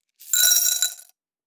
Saving Coins 02.wav